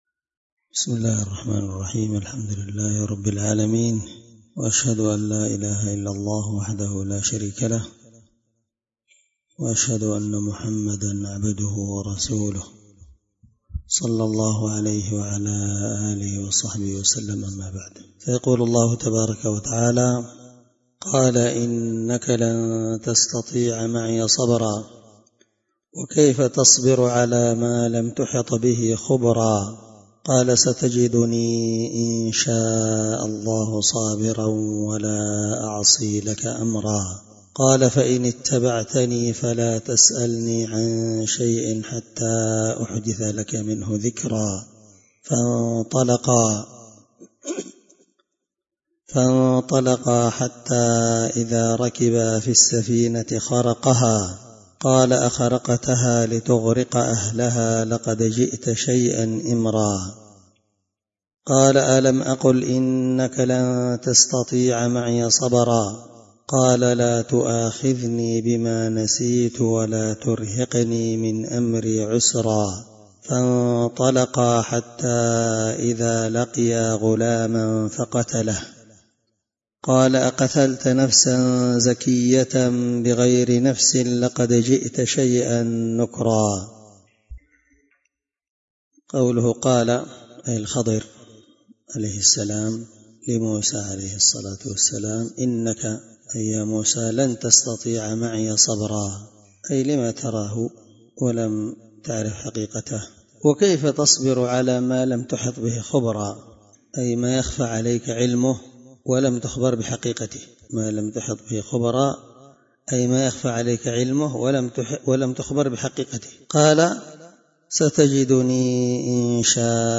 الدرس23 تفسير آية (66-74) من سورة الكهف